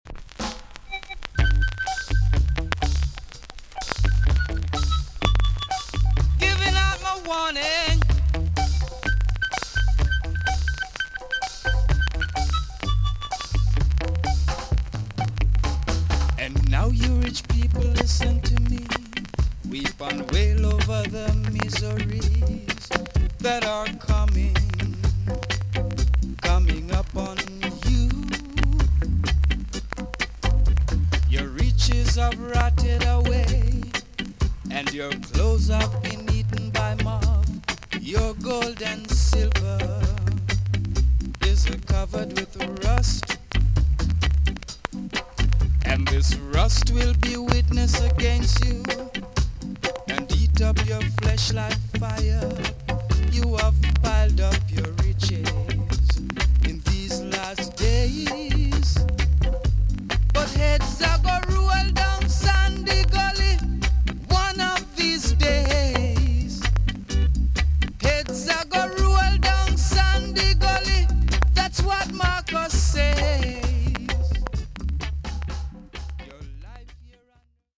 TOP >REGGAE & ROOTS
VG+ 軽いチリノイズがあります。
NICE ROOTS VOCAL TUNE!!